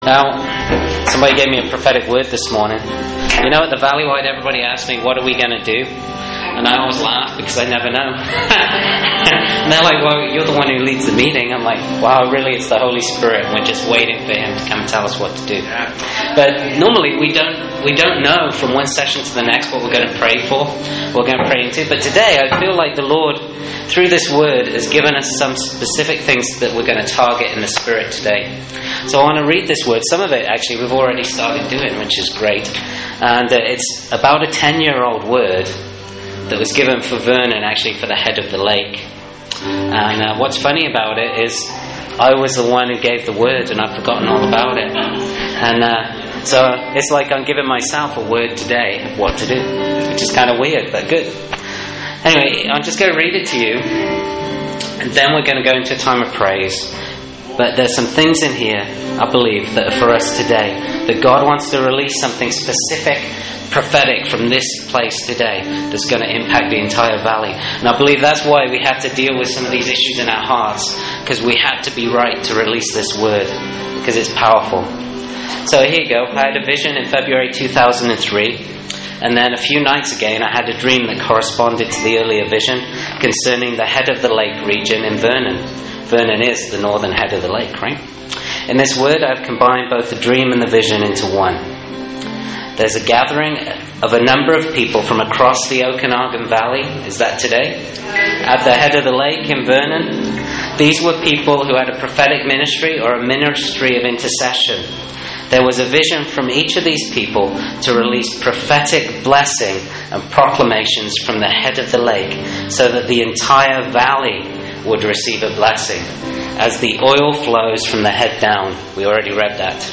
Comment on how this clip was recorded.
From Vernon Valleywide Gathering, 5th May, 2012: